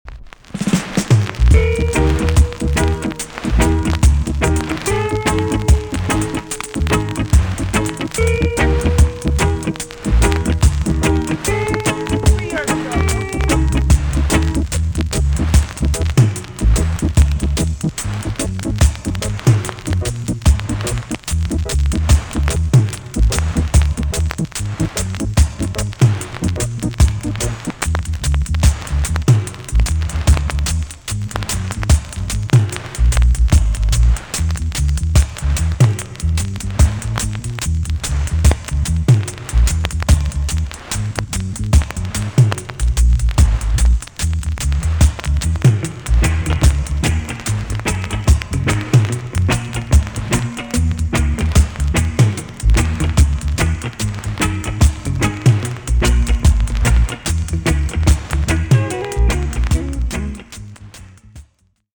TOP >REGGAE & ROOTS
VG ok チリノイズが入ります。